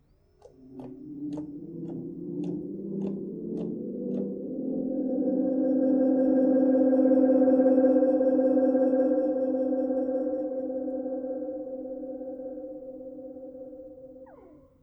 System-1 Memory Set 6: Drums and Sound Effects